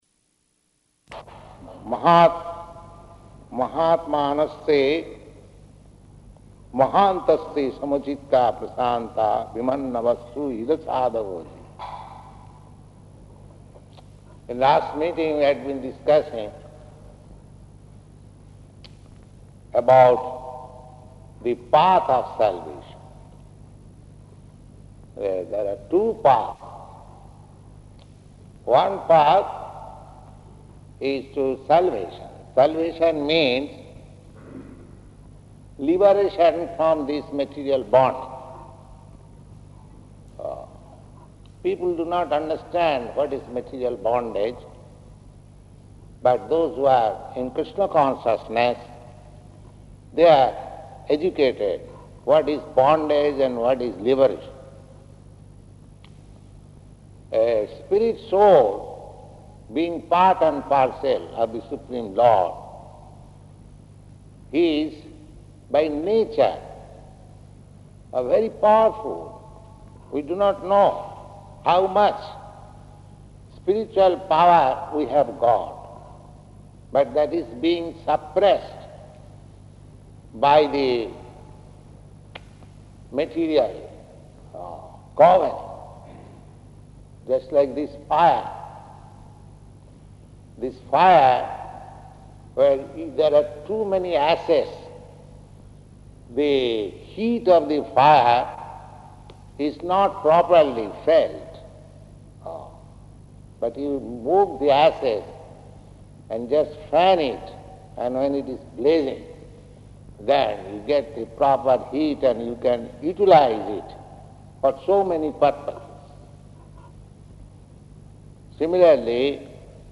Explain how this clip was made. Location: London